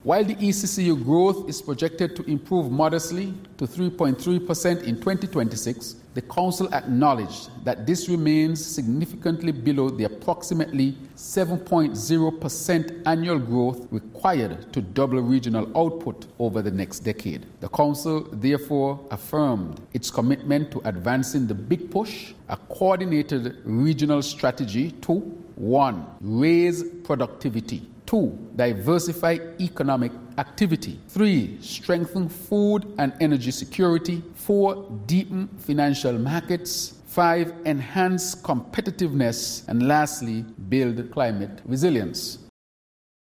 In presenting the communique for the meeting, Prime Minister and Minister of Finance, the Hon. Dr. Terrance Drew spoke about projected growth: